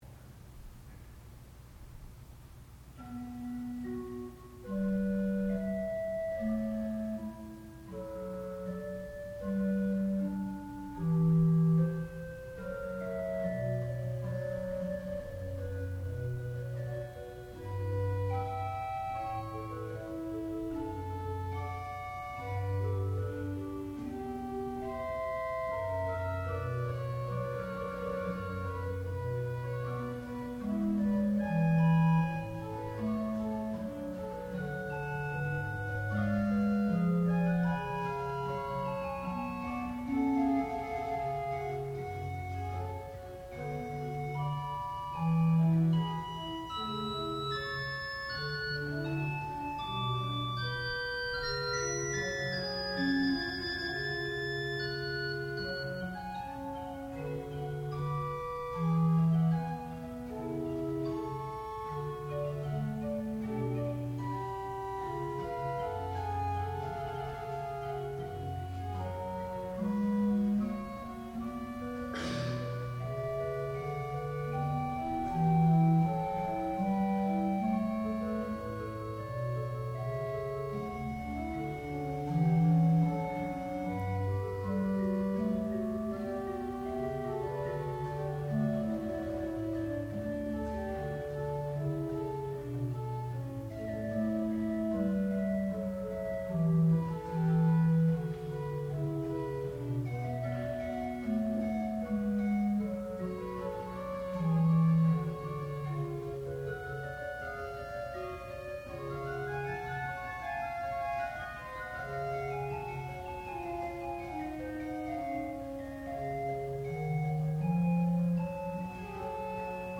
sound recording-musical
classical music
Graduate Recital